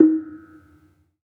Gamelan Sound Bank
Kenong-dampend-D#3-f.wav